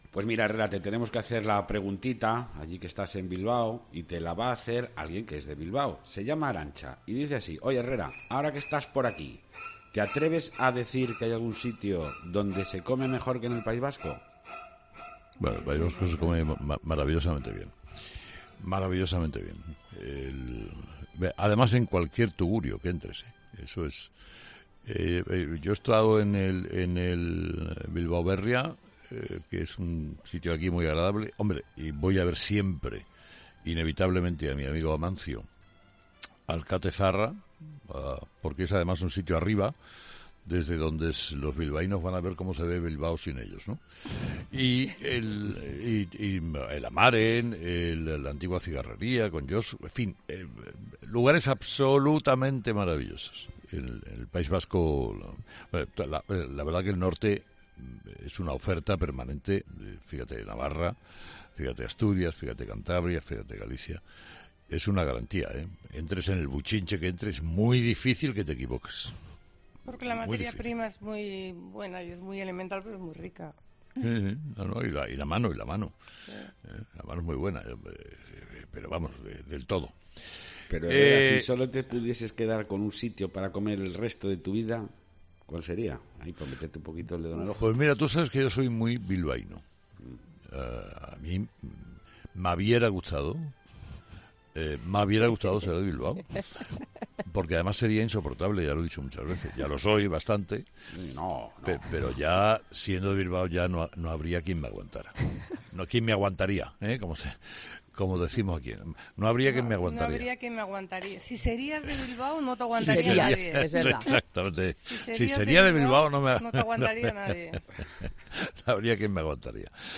"Tú sabes que yo soy muy bilbaíno. Me hubiera gustado ser de Bilbao, porque además sería insoportable, ya lo he dicho muchas veces. Ya lo soy bastante, pero siendo de allí ya no habría quien me aguantaría, como decimos aquí", expresa Herrera entre risas al intentar "mojarse" por el sitio en el que se quedaría a comer para el resto de su vida, si no le permitiesen ninguno más.
Está clara la querencia de Carlos Herrera por la ciudad vizcaína y desde allí, desde COPE Bilbao, ha podido este jueves dirigir el primer tramo de 'Herrera en COPE'.